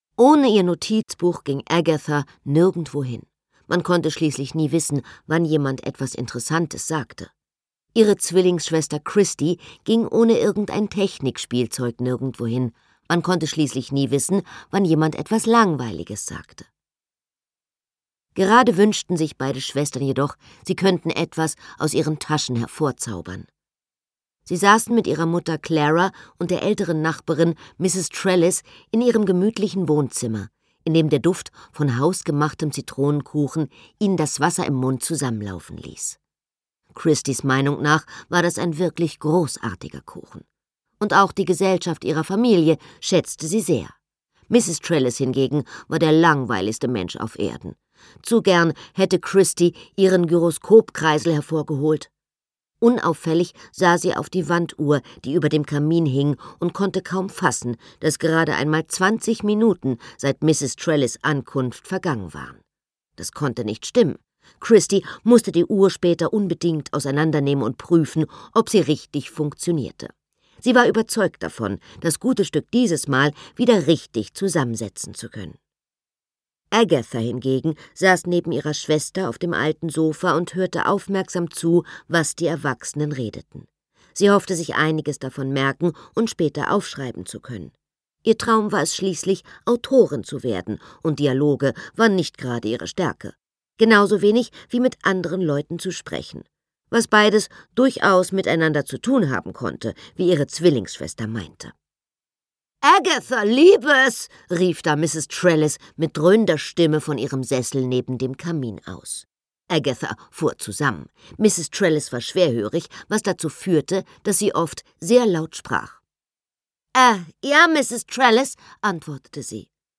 Ihre unverwechselbare, ausdrucksstarke Stimme macht sie zudem zur gefragten Hörbuchsprecherin. 2008 erhielt Anna Thalbach den Deutschen Hörbuchpreis in der Kategorie »Beste Interpretin«.
Sprecher Anna Thalbach